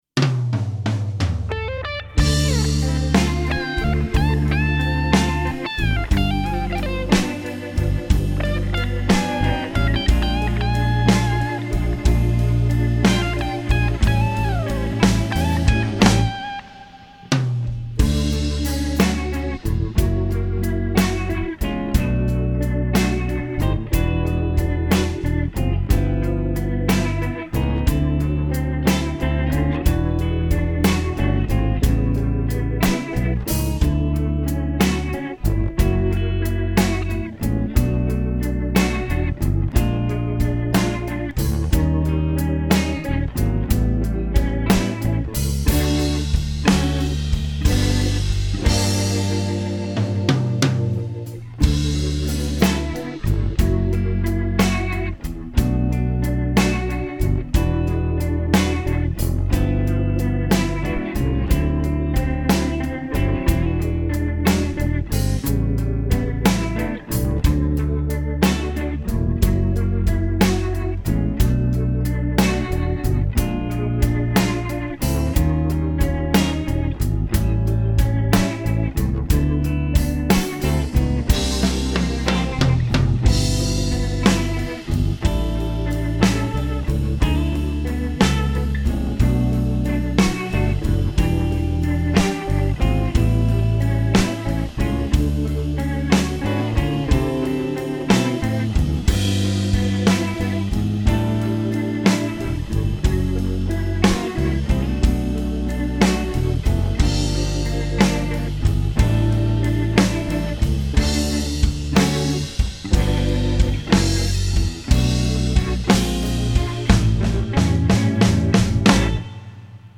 R&B/Jazz/Blues 1962 Gibson 335 w/PAFs, Speed Shop 5E3 w/A12Q / No EQ or Processing
Check out the sweet & clean "West Coast" solo at 3:08!
Signal chain: DIY Teletronix La2a and Pultec EQP-1a, Revolution Redd.47 Preamp, Purple Audio MC76, Altec 438a. Microphones: Vintage Shure Unidyne III, Sennheiser e906 (close) and AKG 414 (room). Speakers: Speed Shop A12Q, Celestion Blue, G12M Heritage. Amps: Various RecProAudio Tweed Deluxe P2P and Studio-Series with NOS Tubes: RCA 6V6GT, RCA 12AX7, RCA 12AY7, RCA 5Y3.